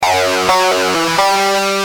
Lead_a2.wav